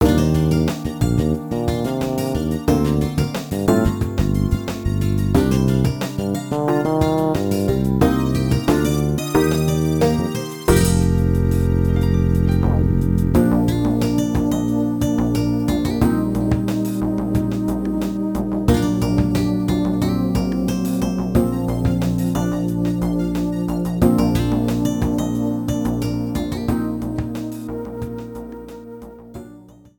Ripped with Nitro Studio 2
Cropped to 30 seconds, fade out added